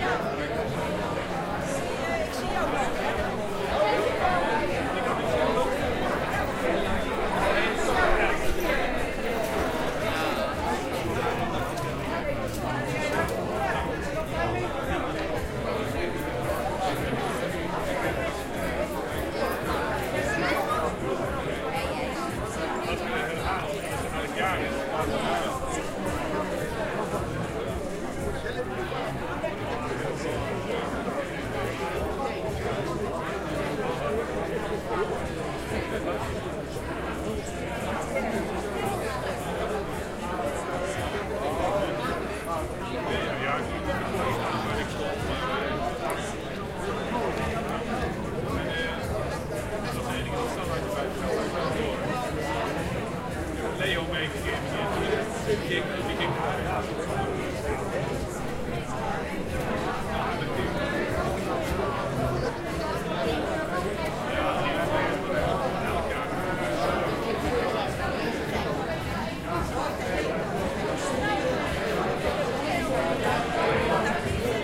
marketLoop.ogg